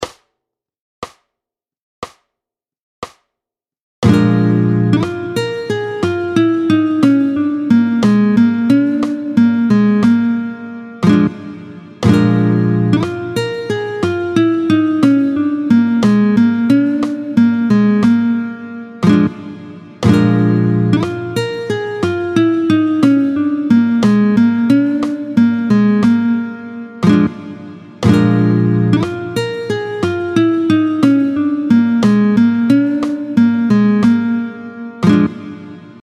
31-08 Un « plan » de blues en Sib, tempo 60
31-09-Plan-de-blues.mp3